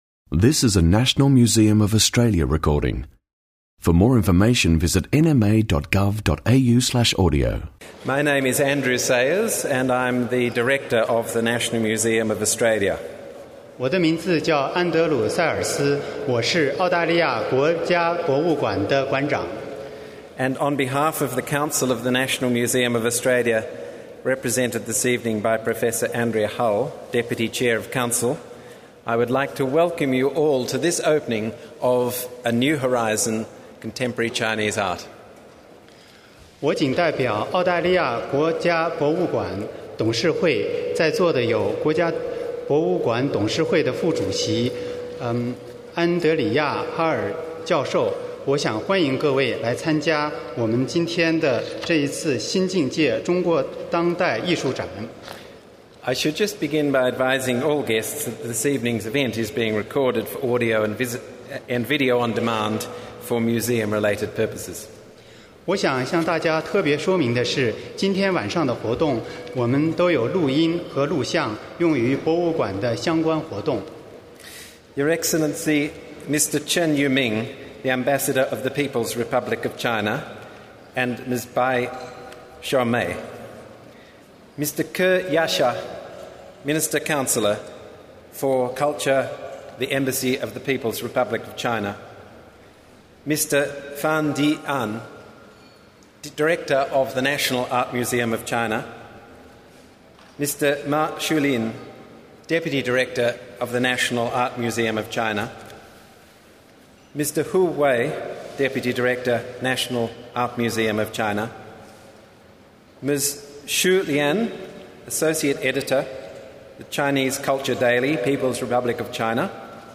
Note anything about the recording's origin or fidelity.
Exhibition launch: A New Horizon: Contemporary Chinese Art | National Museum of Australia